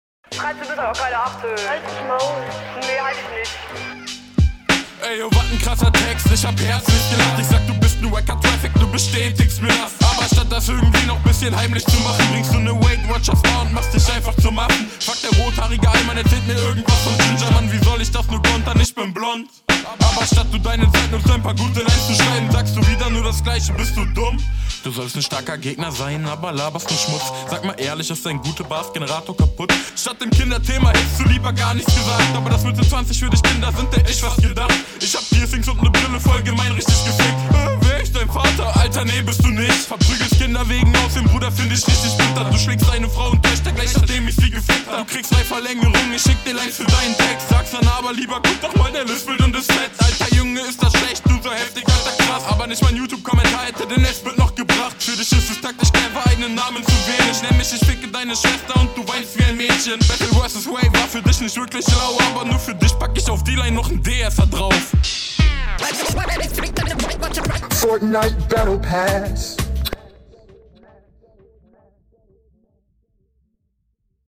Du kommst insgesamt nicht ganz so souverän auf dem Beat wie dein Gegner.
Flowlich leider unterlegen, da waren 1,2 unsaubere zu schnelle …